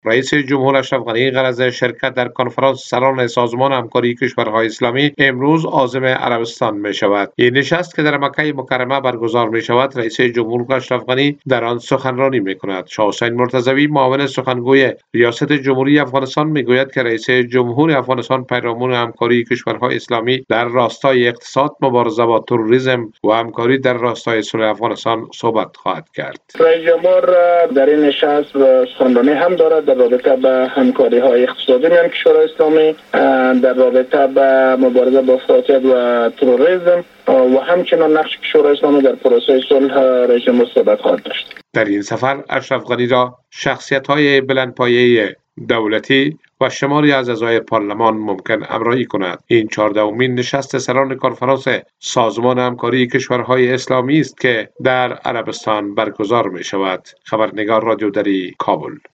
جزئیات بیشتر در گزارش خبرنگار رادیو دری: